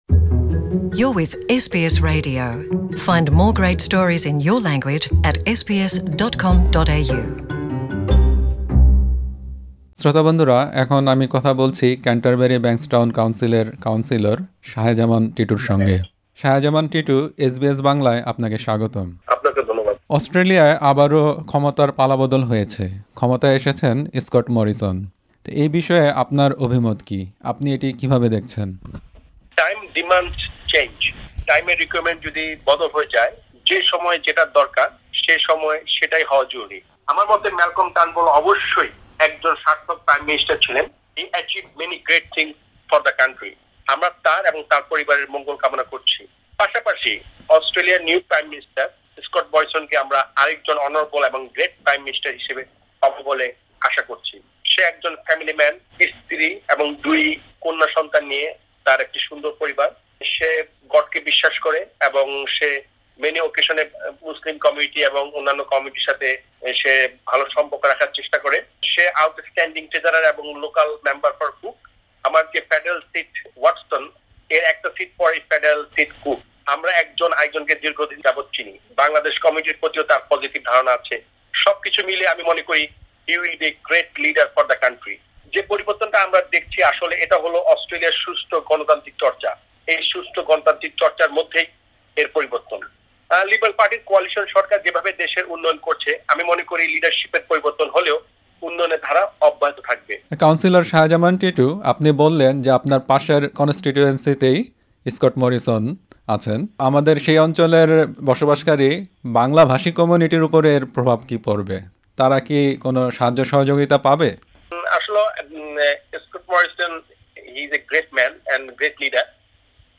অস্ট্রেলিয়ায় নেতৃত্বের পালা বদল: কাউন্সিলর মোহাম্মদ শাহে জামান টিটুর সাক্ষাৎকার
অস্ট্রেলিয়ায় লিডারশিপ স্পিল নিয়ে এসবিএস বাংলার সঙ্গে কথা বলেছেন কেন্টারবেরি-ব্যাংকসটাউন কাউন্সিলের কাউন্সিলর মোহাম্মদ শাহে জামান টিটু।